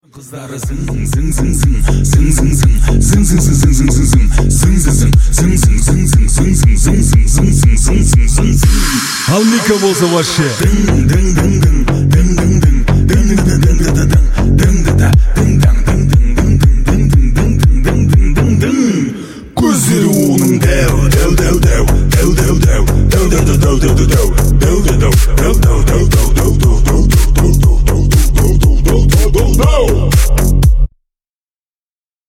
• Качество: 320, Stereo
мужской голос
забавные
веселые
dance
club
казахские